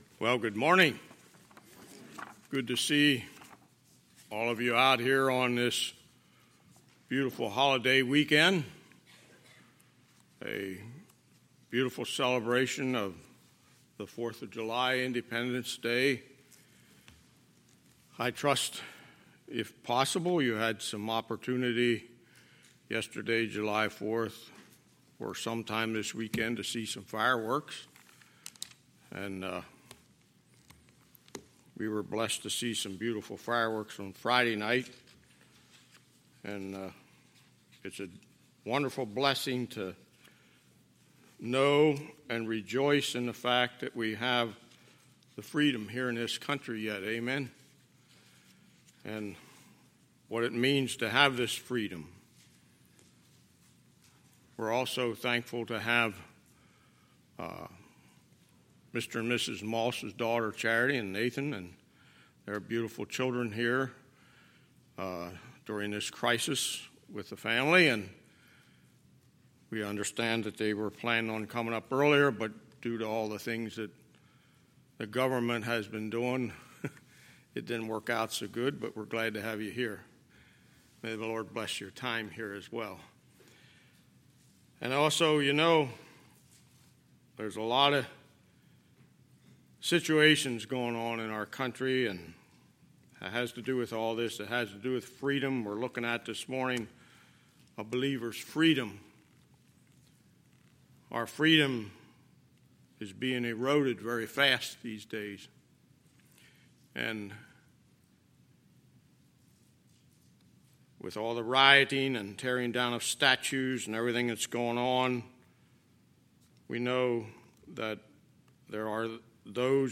Sunday, July 5, 2020 – Sunday Morning Service
Sermons